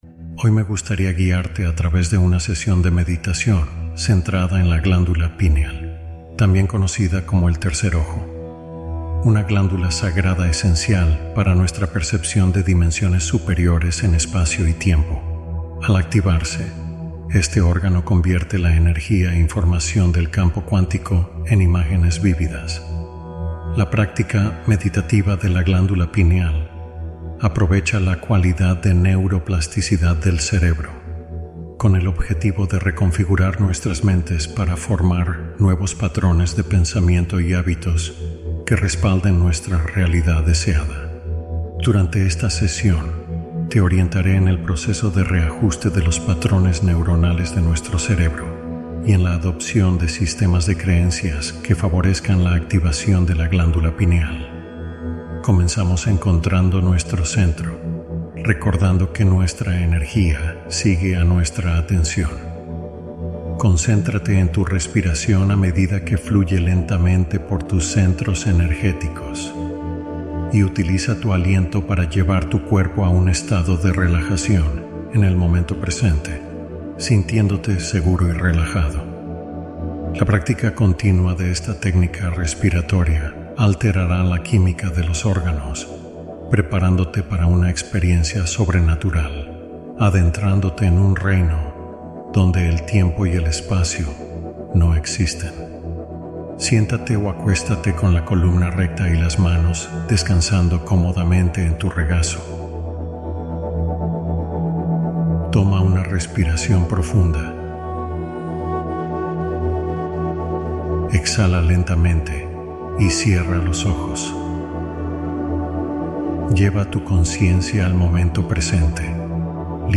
Meditación de activación de la glándula pineal en quince minutos